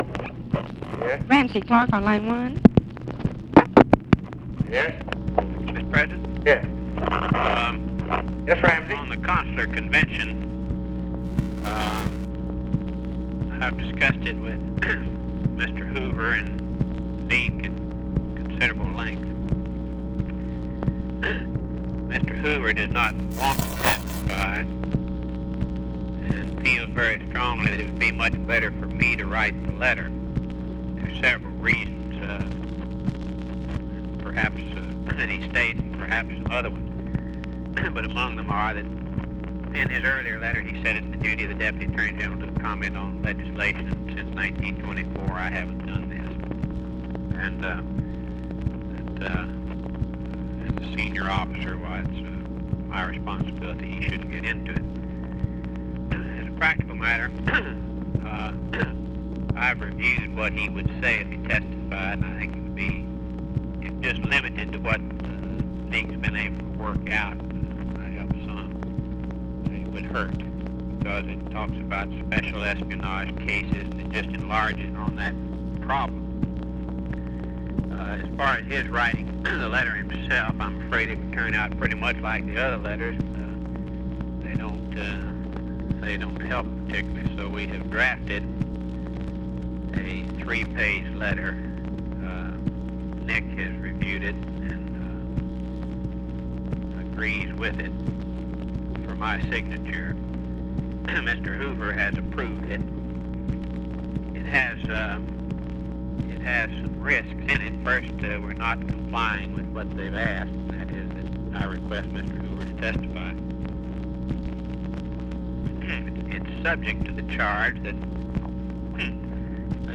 Conversation with RAMSEY CLARK, January 26, 1967
Secret White House Tapes